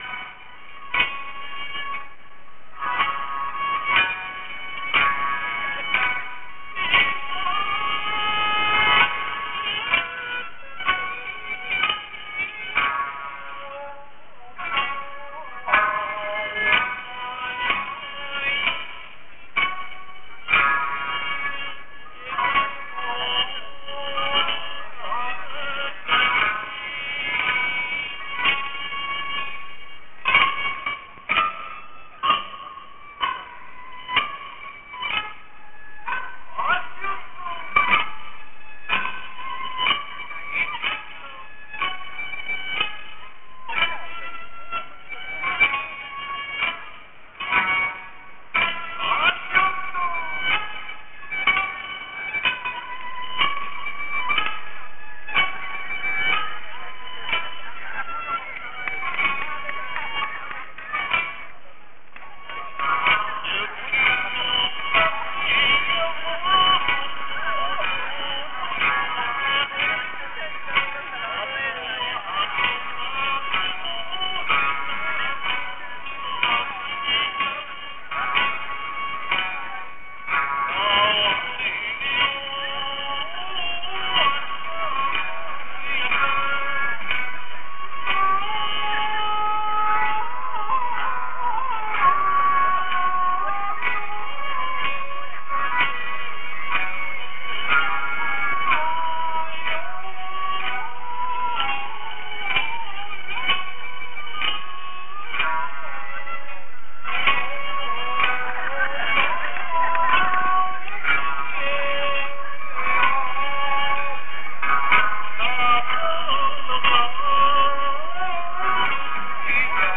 民謡「おわら」は三百年余の歴史を持ち、情緒豊かで気品が高い、哀調の中に優雅さがある。詩的な唄と踊りです。
毎年九月１、２、３日は、数千のぼんぼり、まん灯、まん幕で飾られ、老いも若きも男も女も、揃いのはっぴやゆかた姿に編笠をつけ、三味線、胡弓の音につれて唄い踊り、夜の白むまで町を流して行く。